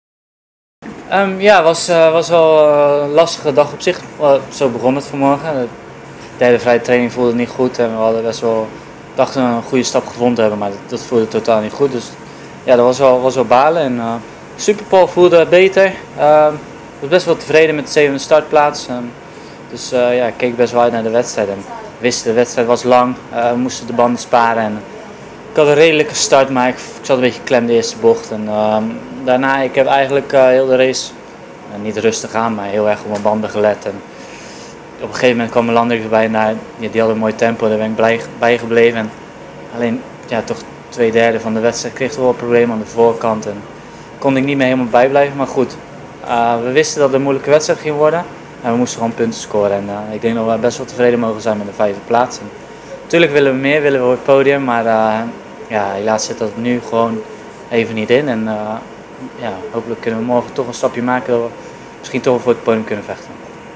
Na afloop spraken we met Van der Mark en vroegen hem om een reactie.